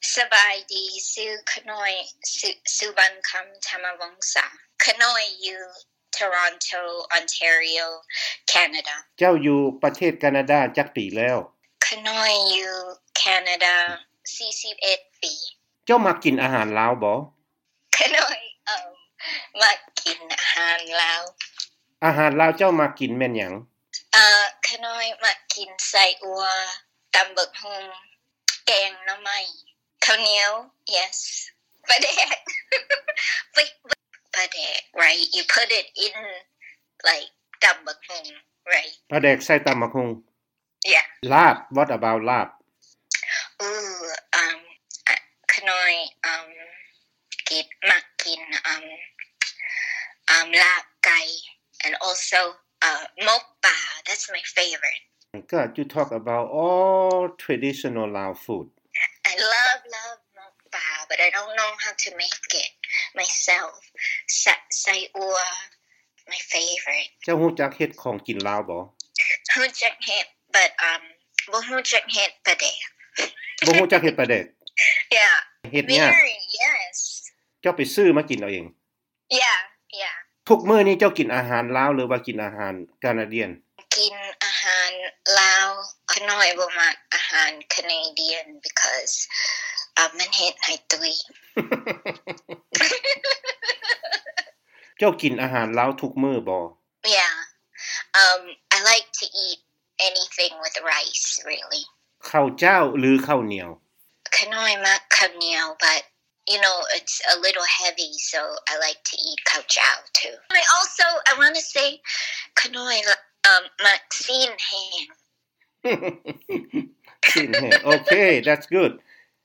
ດ້ວຍສຳນຽງວຽງຈັນ ສູ່ວີໂອເອ ຟັງດັ່ງນີ້: